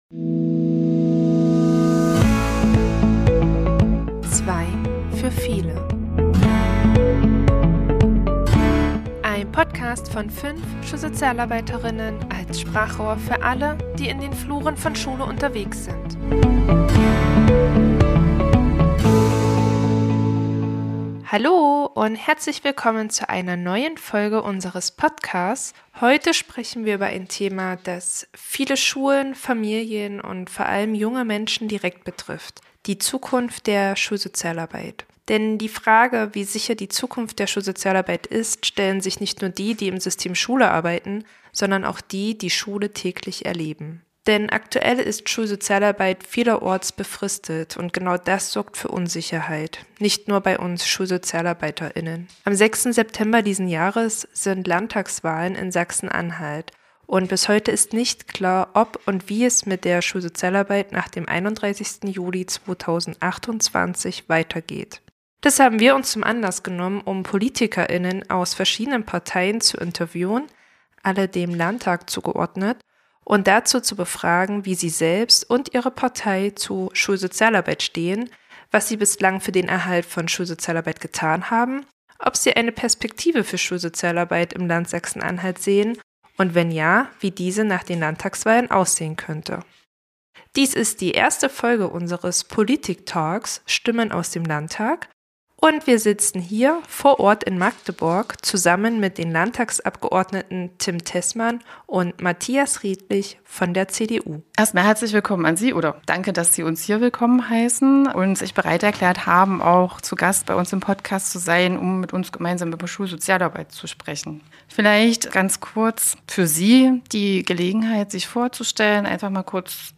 #76 - Stimmen aus dem Landtag: Im Gespräch mit der CDU ~ Zwei für Viele Podcast
Beschreibung vor 1 Woche In dieser Podcastfolge sprechen wir mit den Landtagsabgeordneten Tim Tessmann und Matthias Redlich (CDU) über ein Thema, das viele Schulen aktuell bewegt: Schulsozialarbeit. Gemeinsam beleuchten wir die Bedeutung von Schulsozialarbeit im Schulalltag, diskutieren Herausforderungen bei Finanzierung und Umsetzung und fragen nach den politischen Perspektiven für die Zukunft.